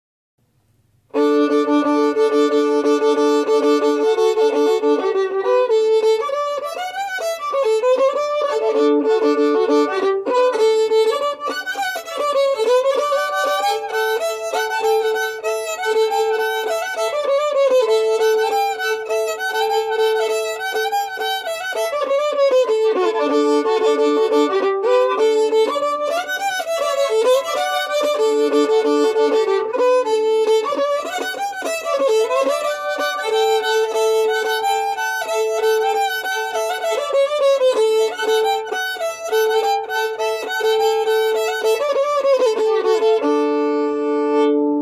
Key: D
Form: Reel
Genre/Style: Old-time